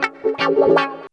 137 GTR 5 -L.wav